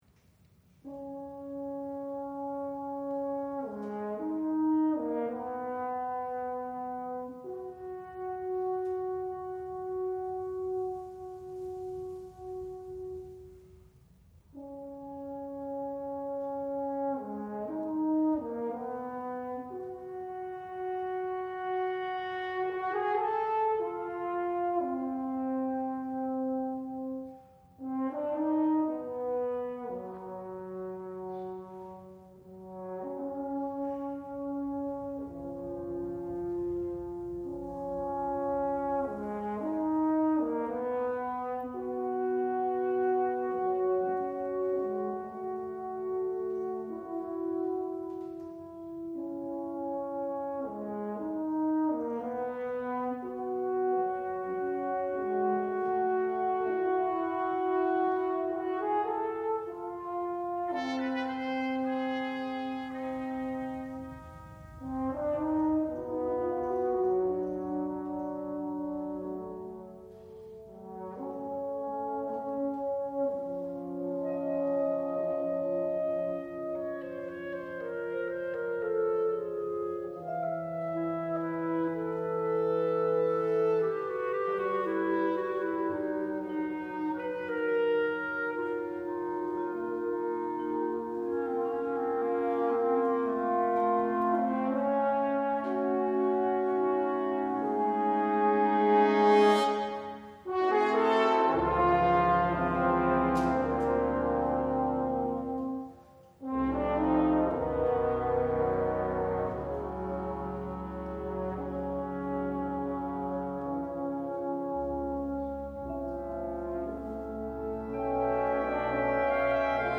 for chamber wind ensemble (20 players)